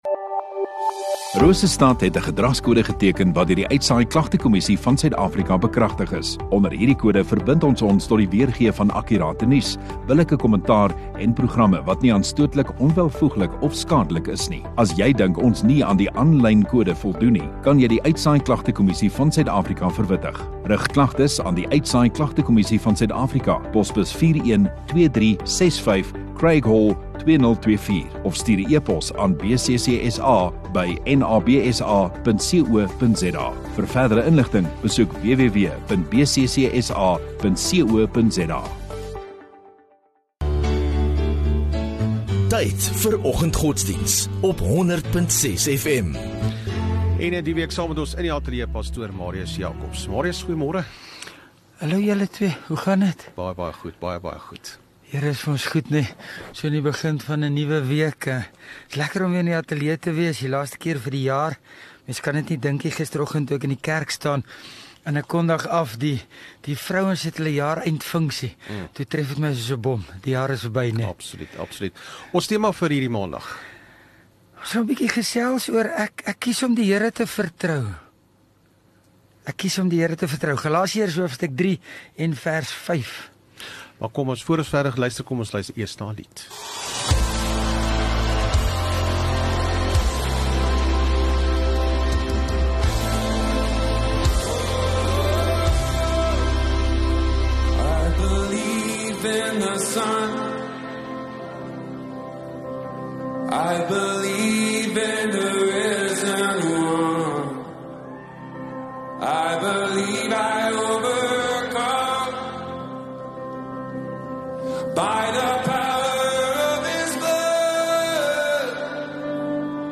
28 Oct Maandag Oggenddiens